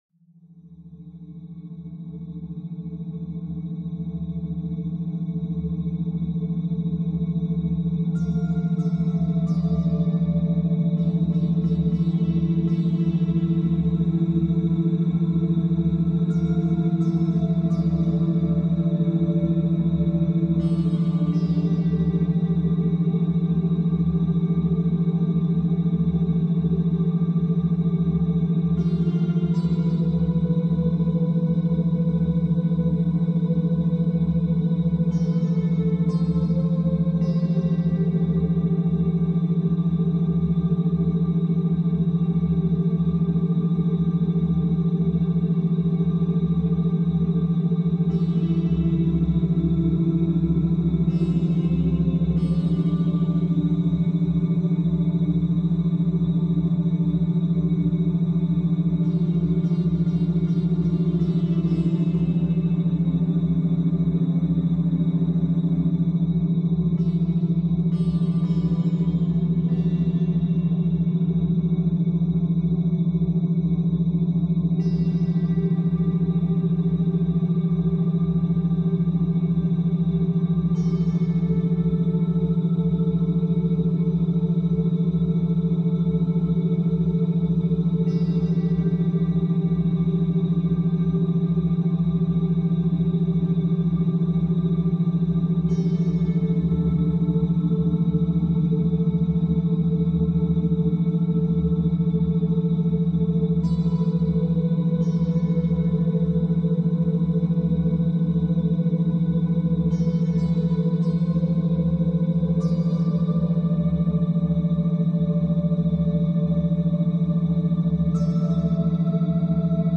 Pomodoro 2h : Pluie et Focus
Aucun bruit parasite, aucune coupure soudaine.